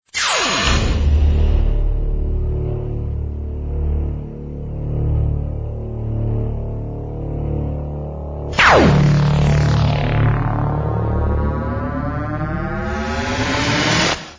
explorer background fx